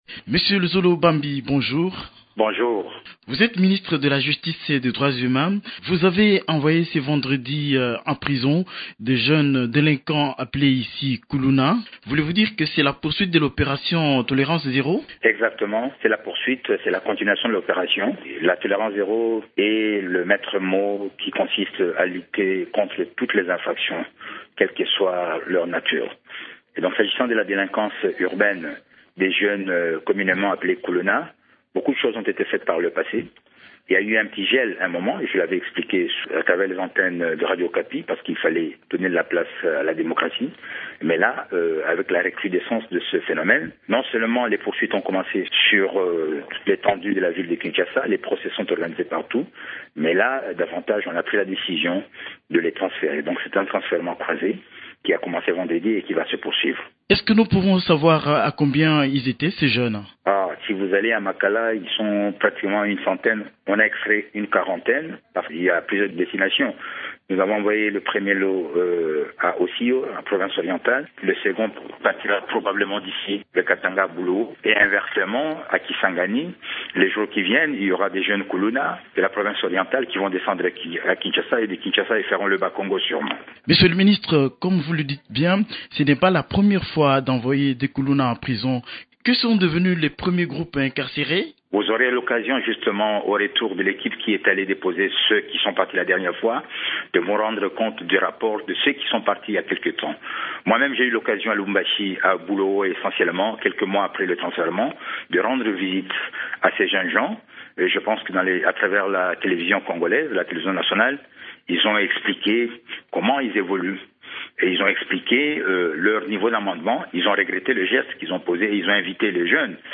Okapi service, Émissions / emploi, chômeur, rémunération